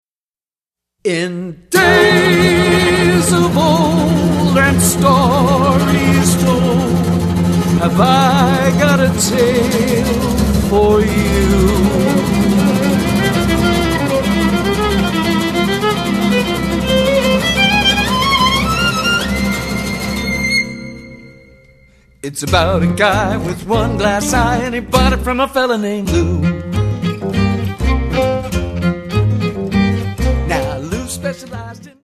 lead vocals, guitars, national steel, harmony vocals
violin
upright bass
percussion
chunk guitar & vibes